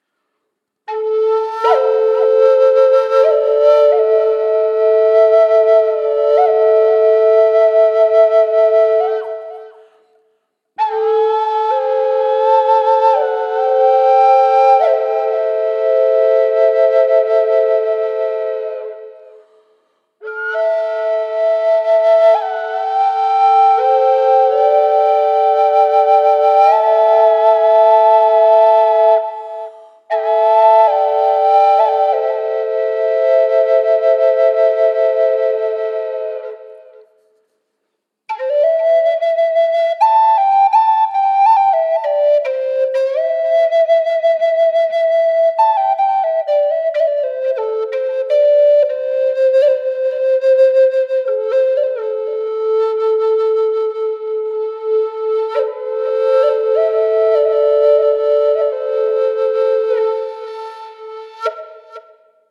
Korkeahko ja lempeä sointitaajuus. Viritetty pentatoniseen molli sävelasteikkoon.
• Tyyli: Triple drone
Ääninäyte kaiku/reverb efektillä:
A4_440hz_tripledrone_pentatoninenmolli_FX.mp3